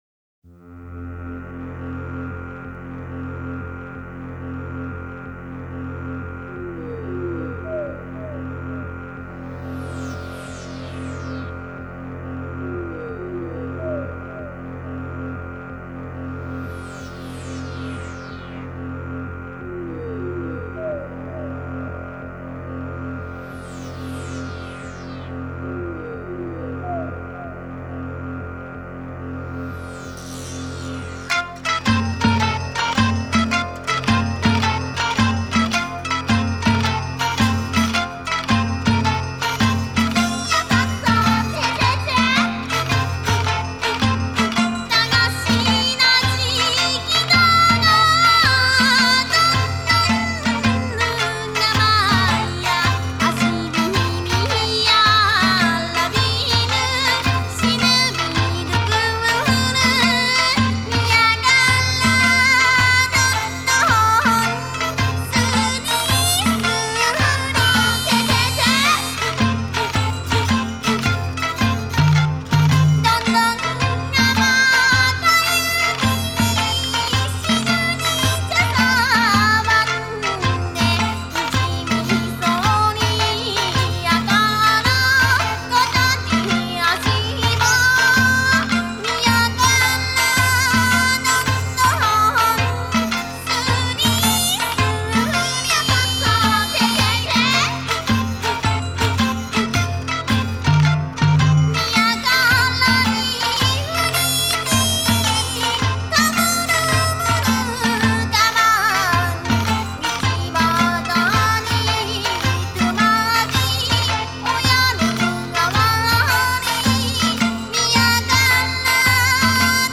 an okinawan folk song sung in uchinaaguchi (okinawan language) and featured in gō